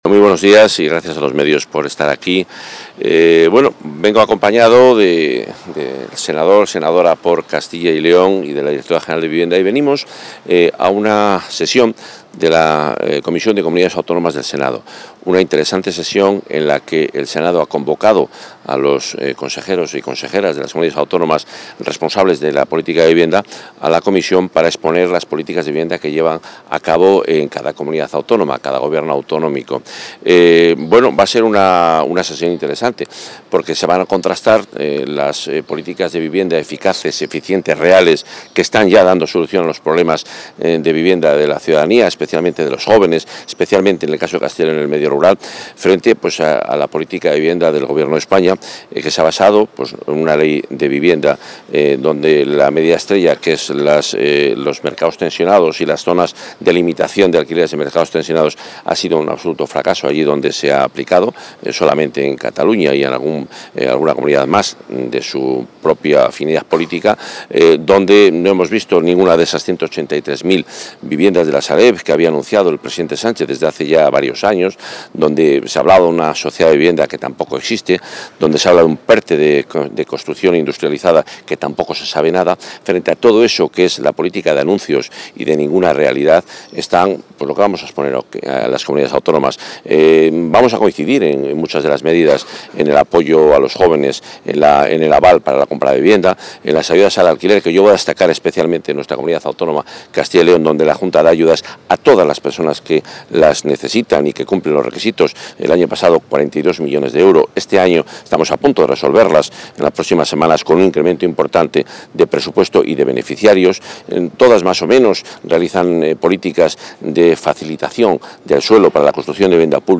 Declaraciones del consejero.
Durante su intervención en la Comisión General de las Comunidades Autónomas, el consejero de Medio Ambiente, Vivienda y Ordenación del Territorio, Juan Carlos Suárez-Quiñones, ha destacado el aumento del 80 % del parque público en alquiler, el impulso al alquiler asequible y las medidas pioneras en suelo y ordenación del territorio.